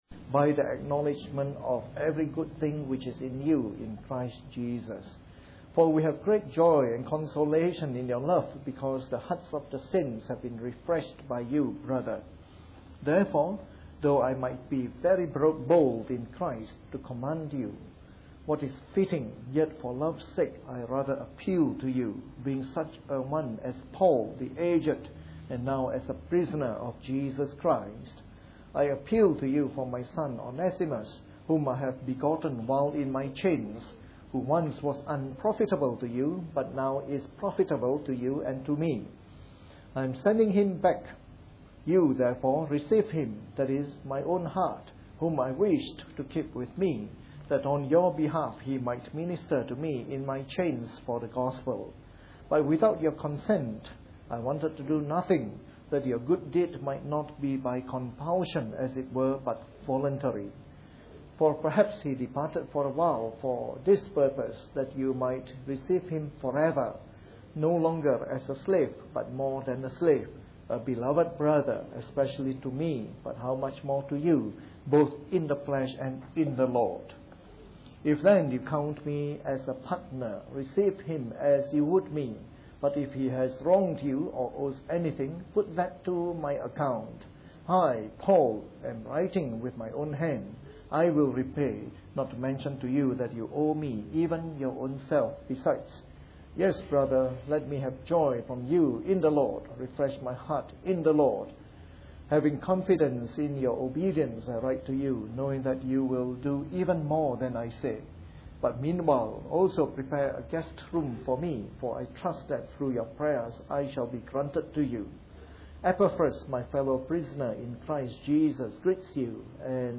A sermon in the morning service covering the Book of Philemon.